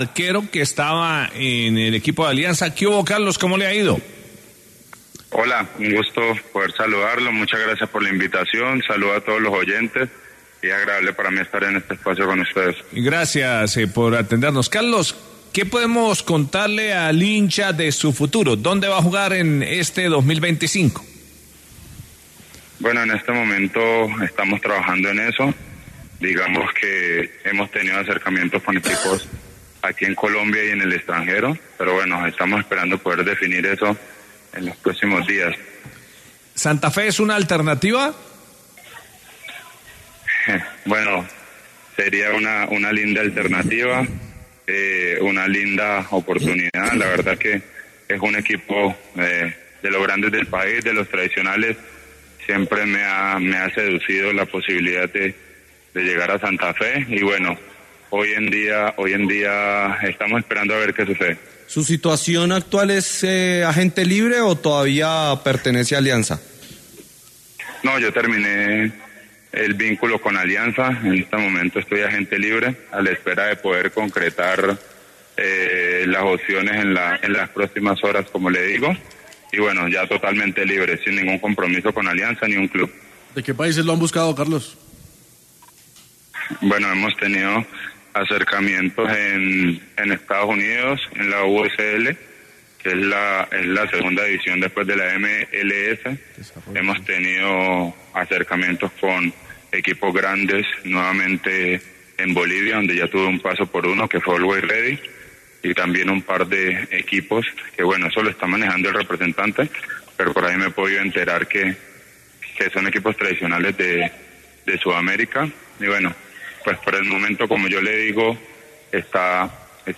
El portero estuvo presente en Caracol Deportes de Caracol Radio y habló sobre su futuro y el gran cariño que tiene por Bogotá. También añadió que tiene ofertas interesantes desde el extranjero para jugar en Bolivia o Estados Unidos.